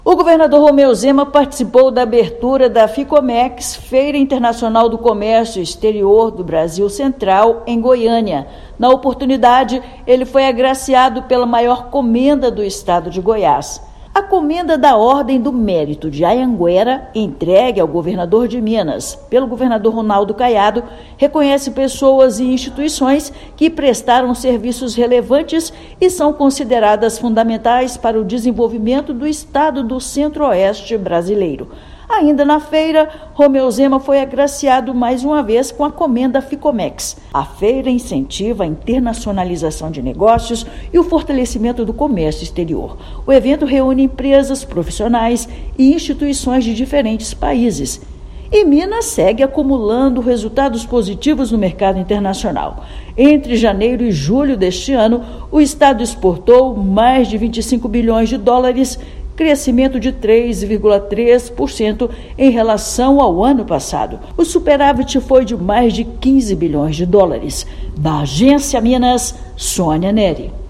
Chefe do Executivo recebeu a Comenda Anhanguera em Goiânia, na abertura do evento voltado ao comércio exterior. Ouça matéria de rádio.